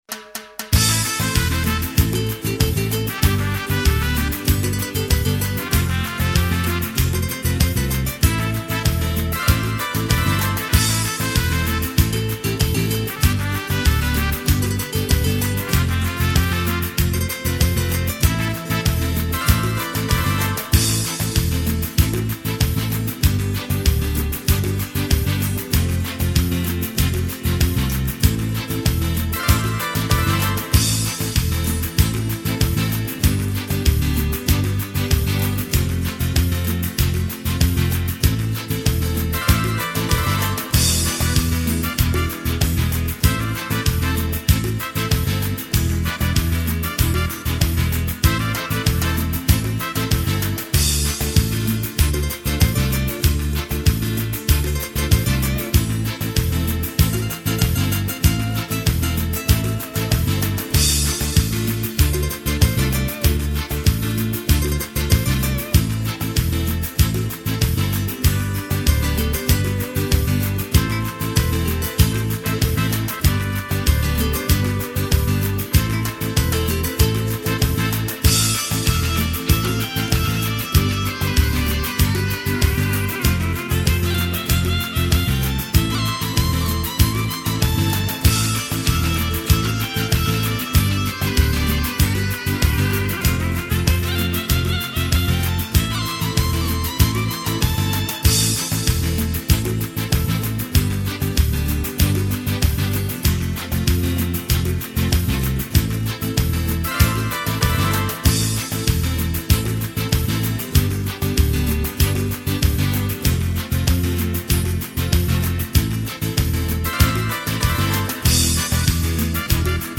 минусовка версия 23371